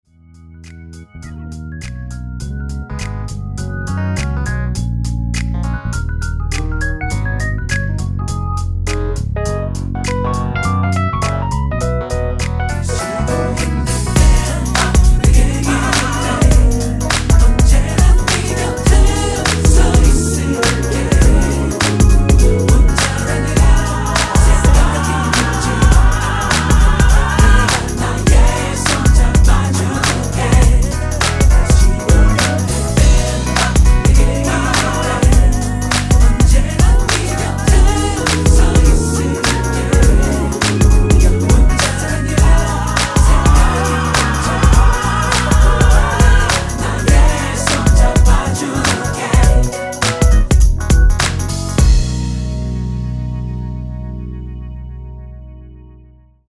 MR은 2번만 하고 노래 하기 편하게 엔딩을 만들었습니다.(본문의 가사와 미리듣기 확인)
원키에서(-1)내린 코러스 포함된 MR입니다.
앞부분30초, 뒷부분30초씩 편집해서 올려 드리고 있습니다.
중간에 음이 끈어지고 다시 나오는 이유는